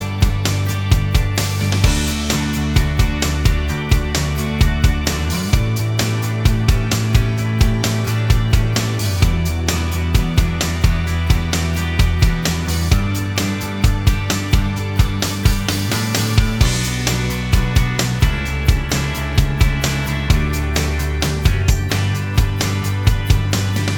Minus Main Guitar Indie / Alternative 4:07 Buy £1.50